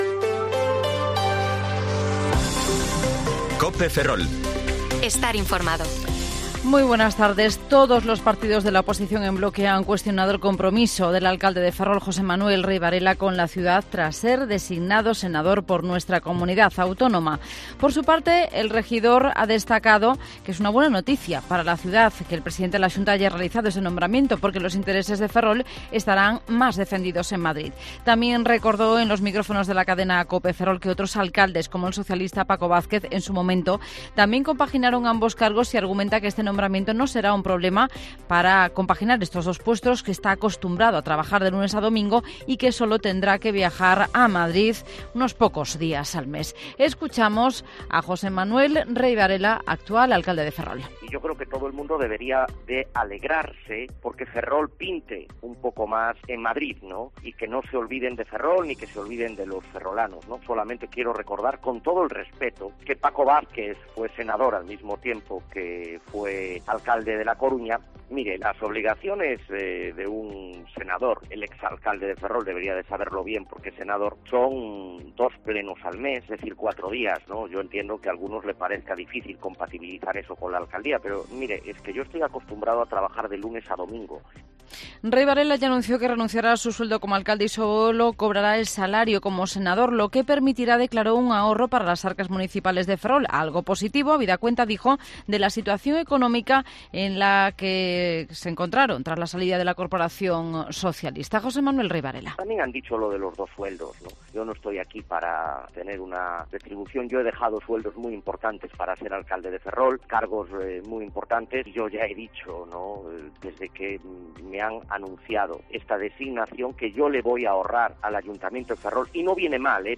Informativo Mediodía COPE Ferrol 27/7/2023 (De 14,20 a 14,30 horas)